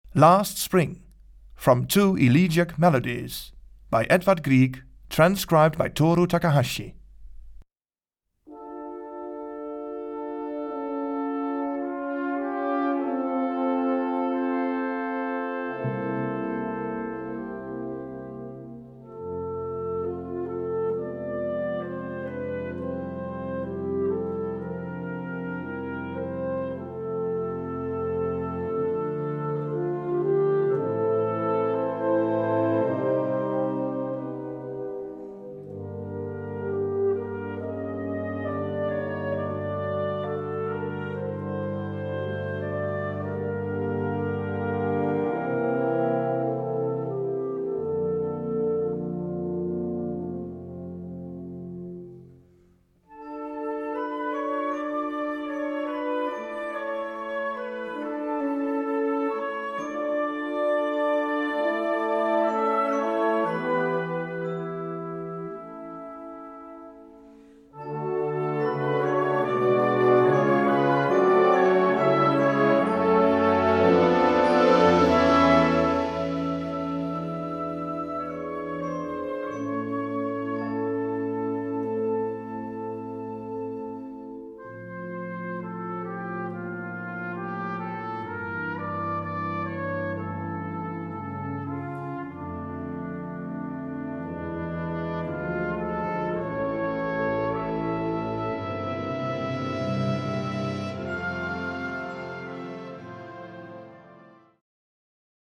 Key: F Major (original key: G Major)